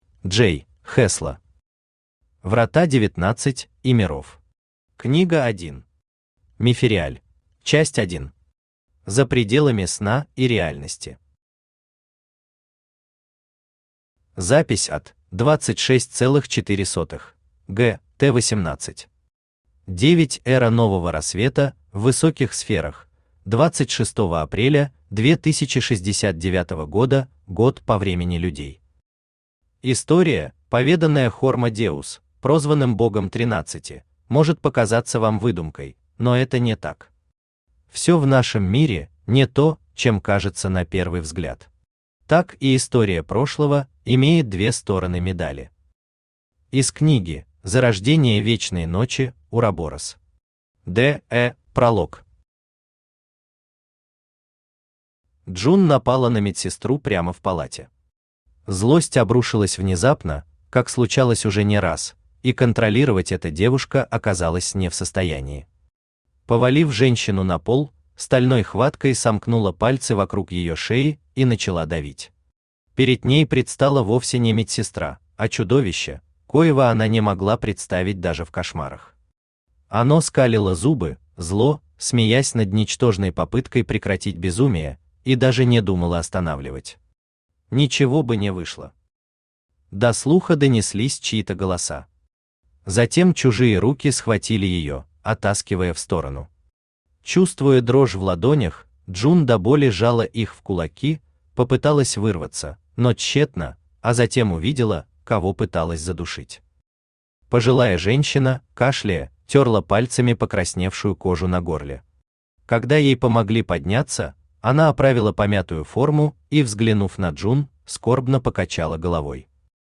Мефериаль Автор J.Hesla Читает аудиокнигу Авточтец ЛитРес.